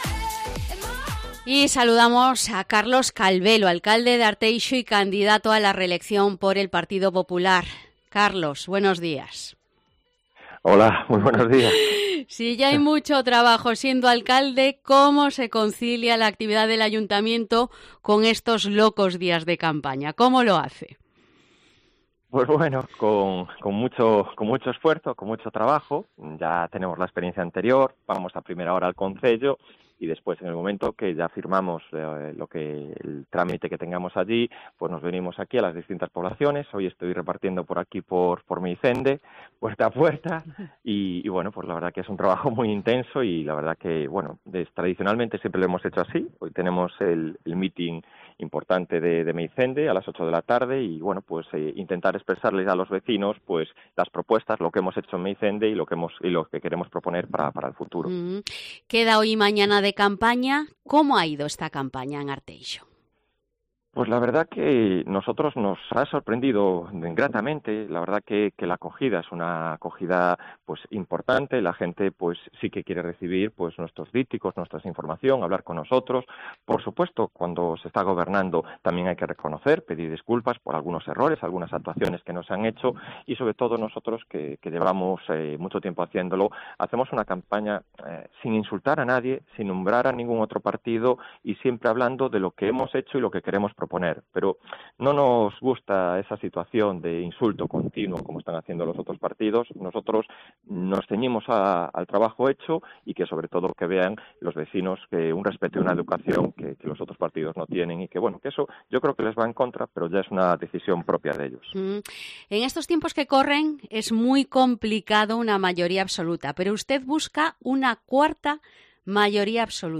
Entrevista al candidato a la reelección a la alcaldía de Arteixo por el PP, Carlos Calvelo